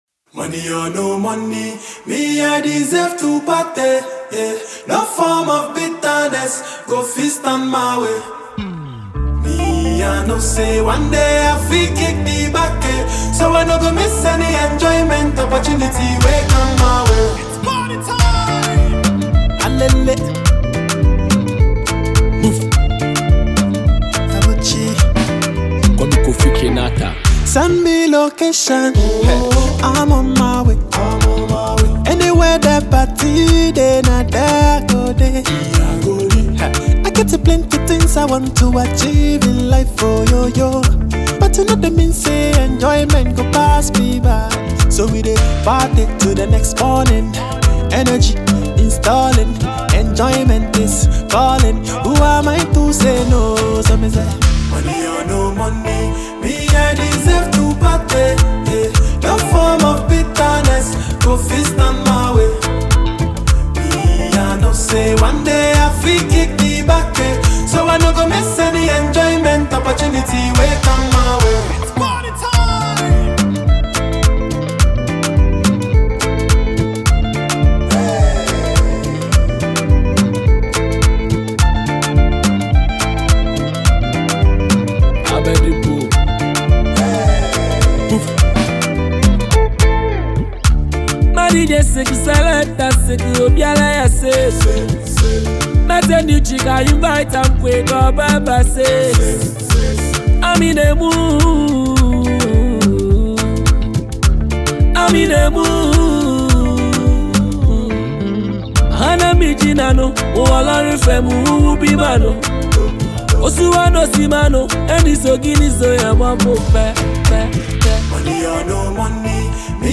Genre: Highlife
and enjoy the vibrant sounds of Ghanaian highlife music.